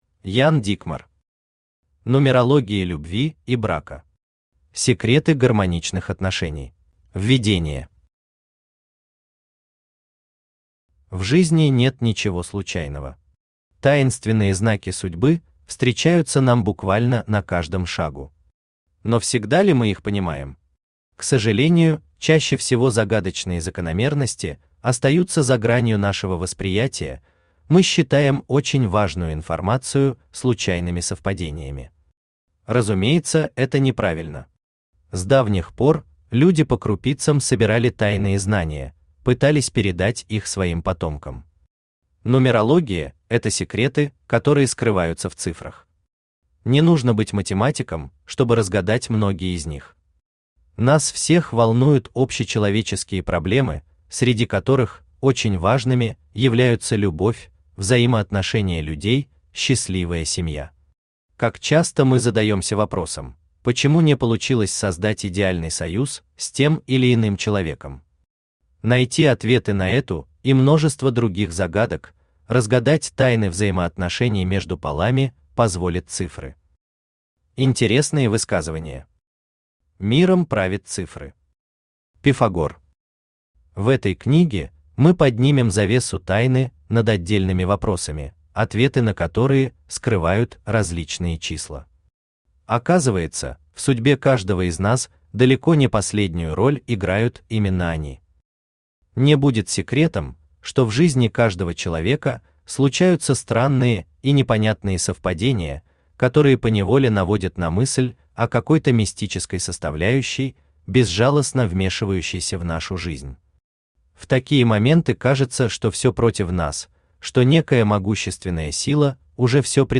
Аудиокнига Нумерология любви и брака. Секреты гармоничных отношений | Библиотека аудиокниг
Aудиокнига Нумерология любви и брака. Секреты гармоничных отношений Автор Ян Дикмар Читает аудиокнигу Авточтец ЛитРес.